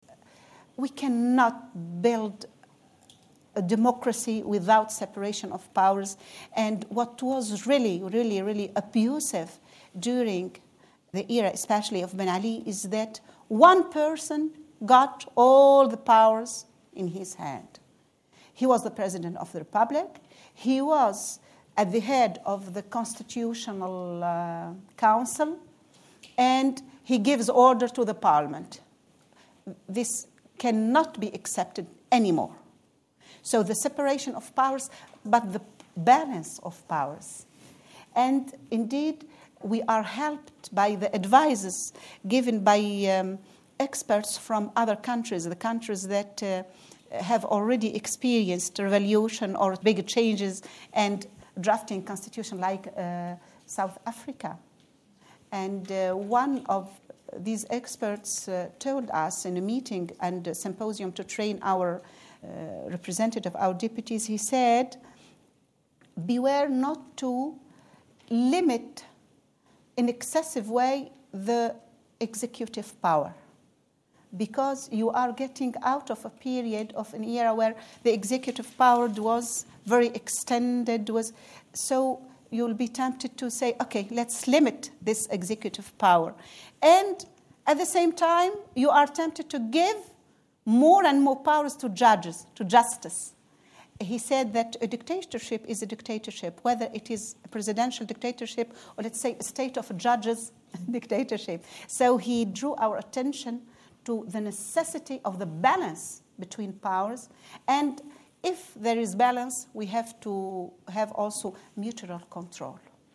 Deputy Speaker of the House, Tunisian ParliamentMerhézia Labidi Maiza speaks at Chatham House 21 March 2012